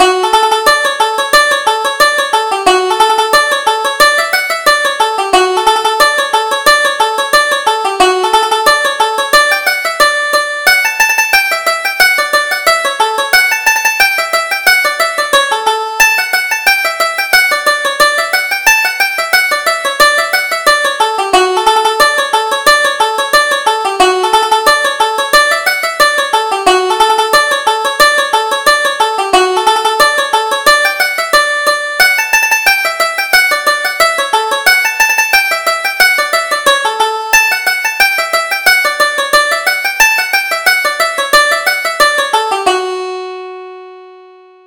Reel: Castle Island